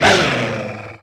Cri de Lougaroc dans sa forme Diurne dans Pokémon Soleil et Lune.
Cri_0745_Diurne_SL.ogg